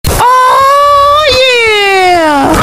Hog Rider Oh Yeah! - Botón de Efecto Sonoro